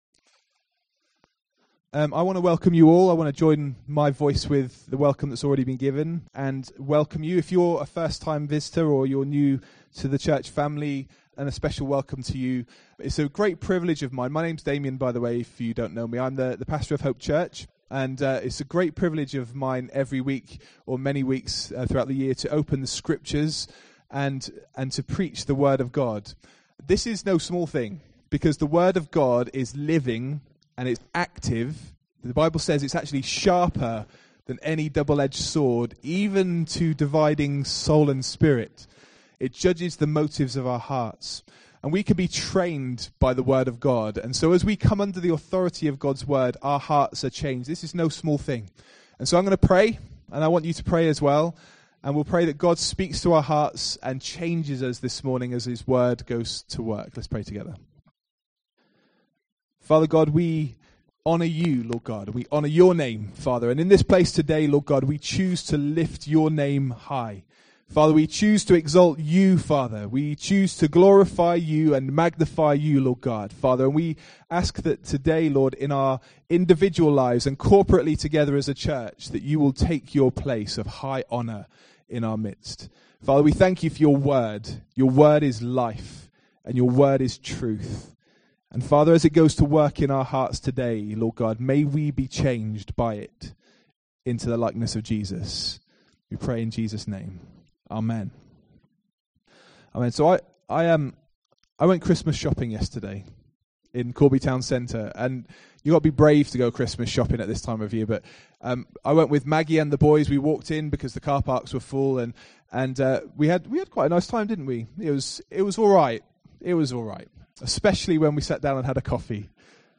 Dec 15, 2019 Good News of Great Joy MP3 SUBSCRIBE on iTunes(Podcast) Notes Sermons in this Series The birth of Jesus is the greatest event in human history and every one is familiar with the traditional nativity story.